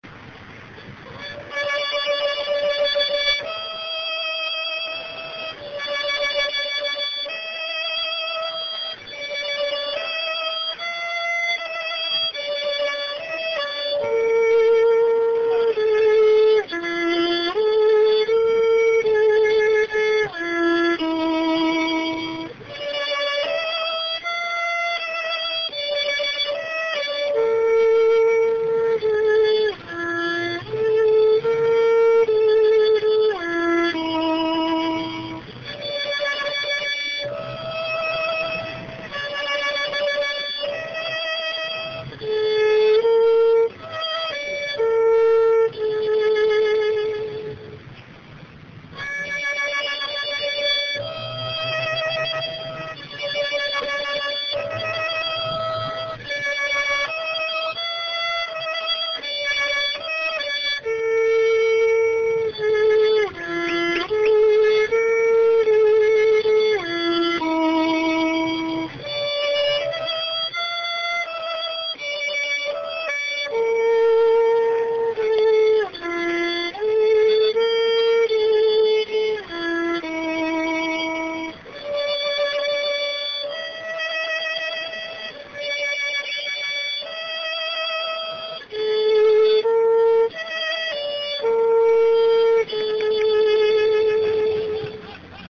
Here are some of the sights and sounds of the ceremony, held in Memorial Park, within sight of the Manitoba Legislature.
(Regrettably much of the beauty was not captured by our cheap little digital audio recorder.)
lanternsviolinsolo.mp3